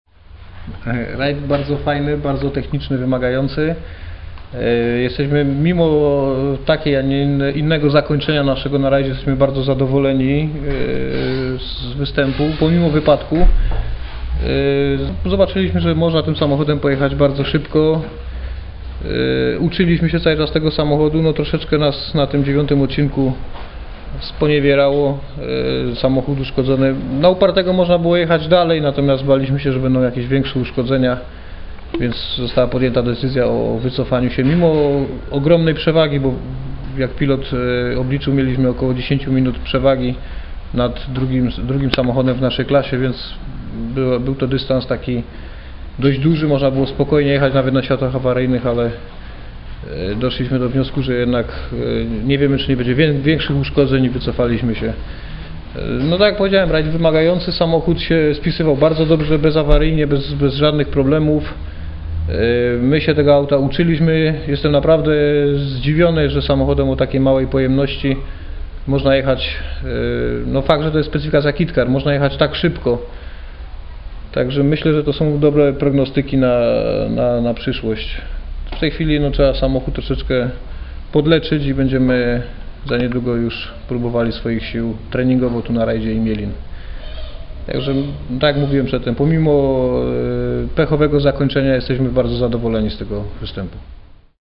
wypowiedź - 33 Rajd Elmot - podsumowanie